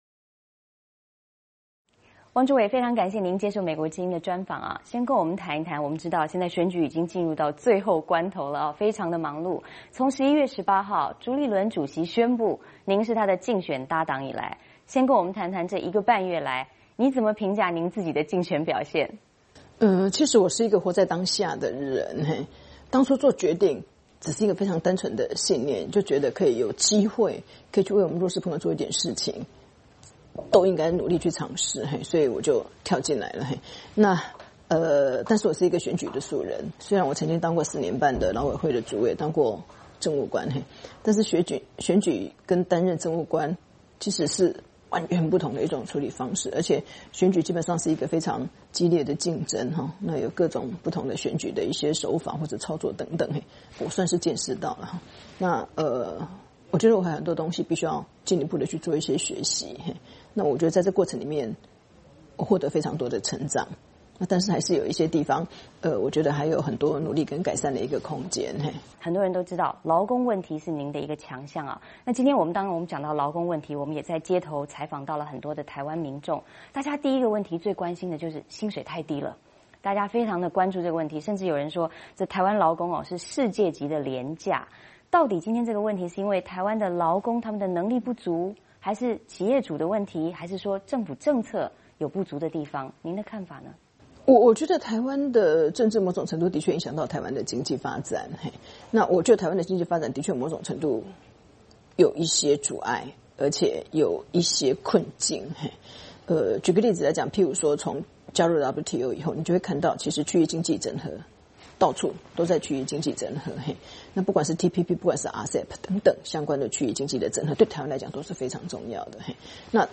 专访台湾国民党副总统候选人王如玄
受访者：台湾总统大选国民党副总统候选人王如玄（以下称王如玄）